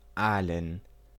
Aalen (German pronunciation: [ˈaːlən]